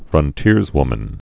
(frŭn-tîrzwmən)